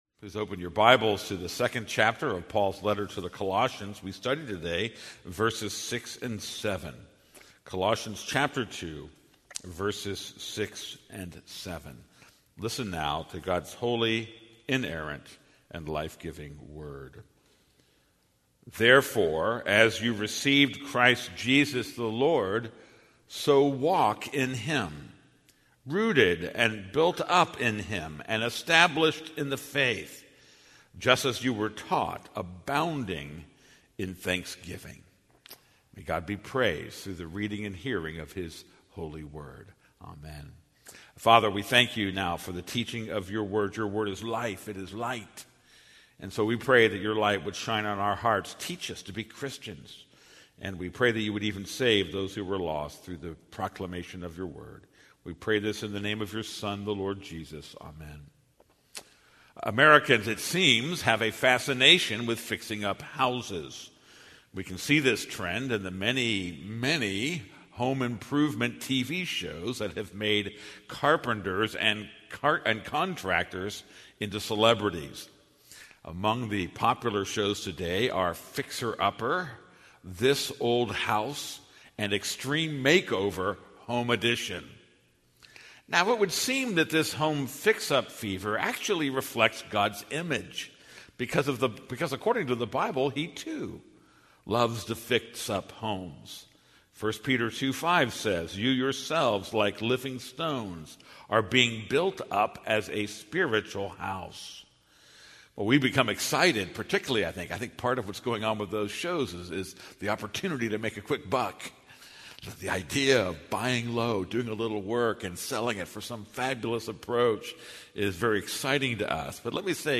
This is a sermon on Colossians 2:6-7.